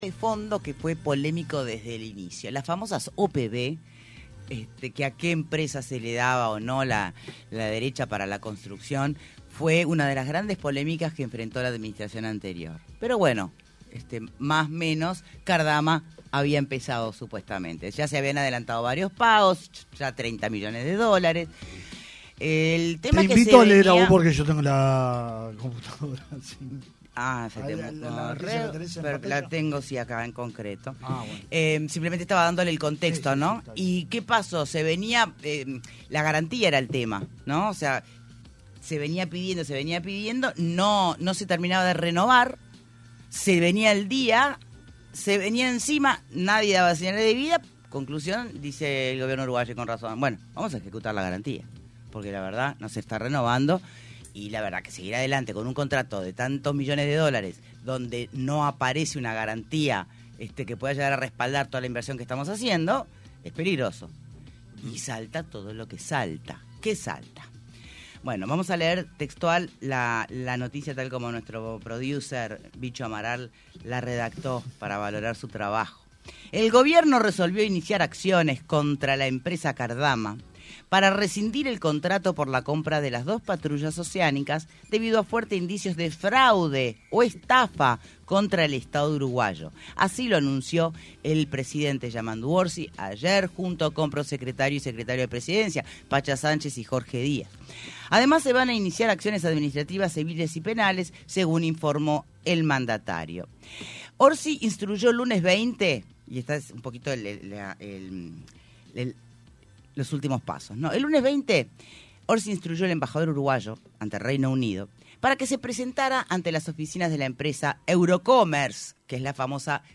AUDIO En entrevista con Punto de Encuentro, el dirigente del Partido Independiente Pablo Mieres habló sobre la polémica por las patrulleras oceánicas del astillero Cardama y la del presidente de ASSE, Álvaro Danza.